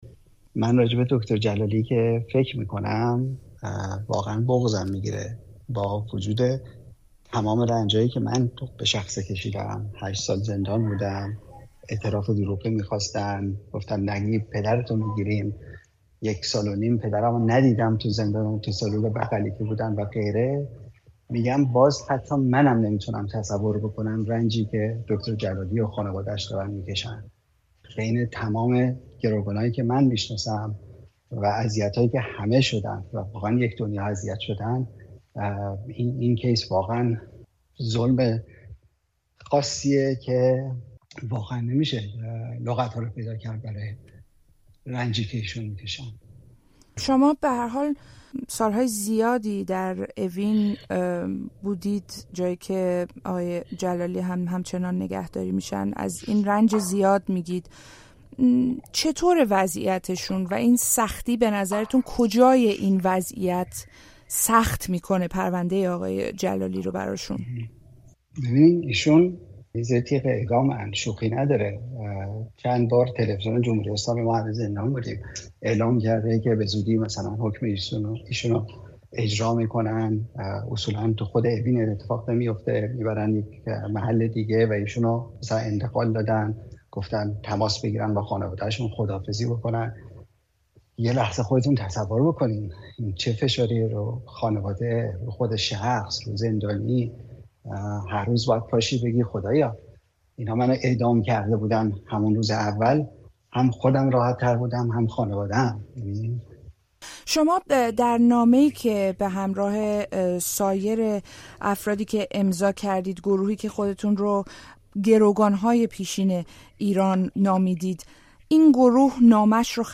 گفتگو با سیامک نمازی درباره احمدرضا جلالی، پزشک زندانی در ایران